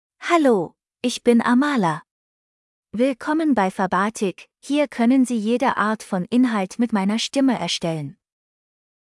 Amala — Female German (Germany) AI Voice | TTS, Voice Cloning & Video | Verbatik AI
Amala is a female AI voice for German (Germany).
Voice sample
Listen to Amala's female German voice.
Female
Amala delivers clear pronunciation with authentic Germany German intonation, making your content sound professionally produced.